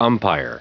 Prononciation du mot umpire en anglais (fichier audio)
Prononciation du mot : umpire